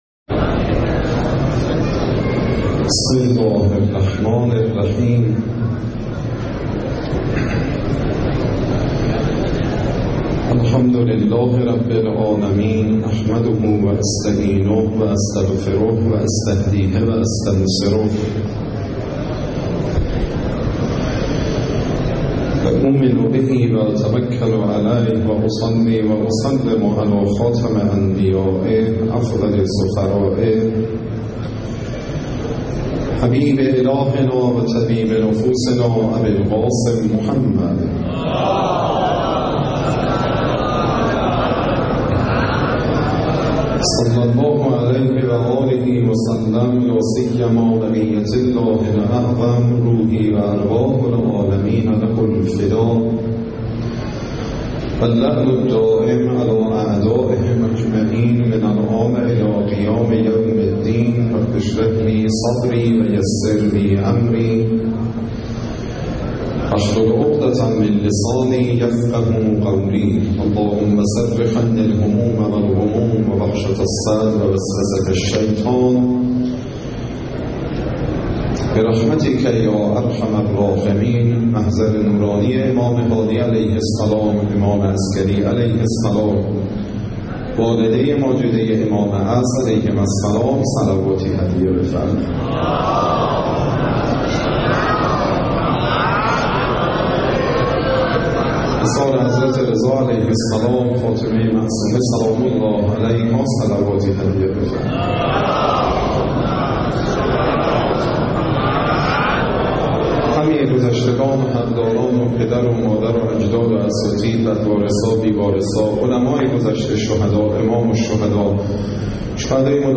دهه آخر صفر97 - شب اول - مسجد اعظم قم